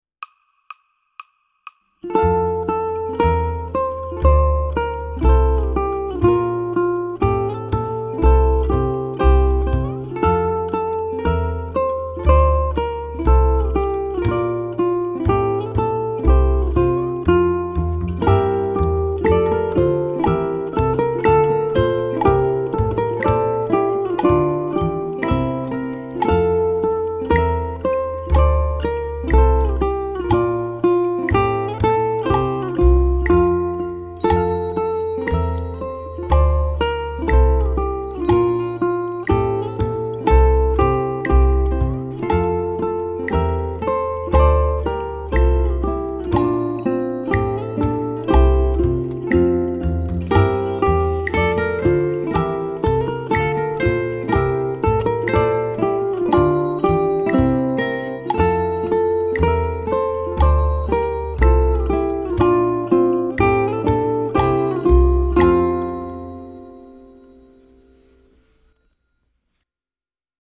Ode to Joy consists mostly of stepwise melody, quarter note driven rhythm and phrase repetitions.
ʻukulele
I used the Down 2X in my audio track along with a rhythm fill at the end of each phrase.